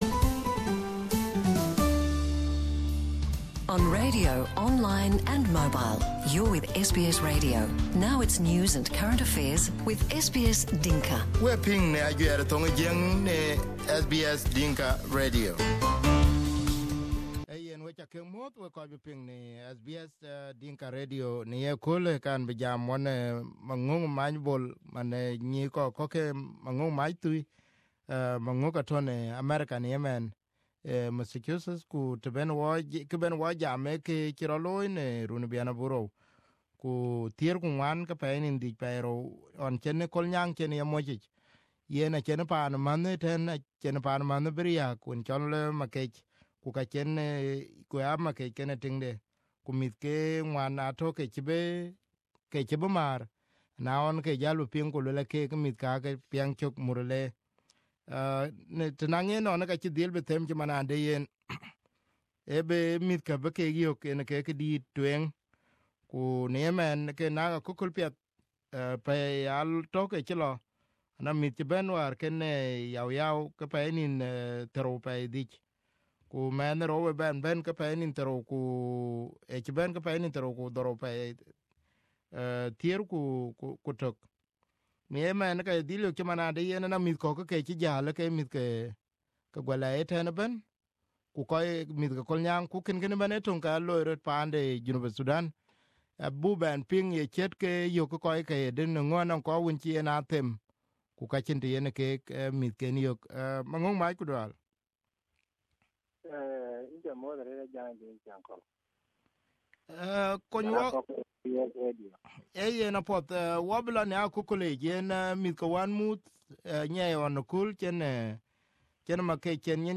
In an exclusive interview on SBS Dinka Radio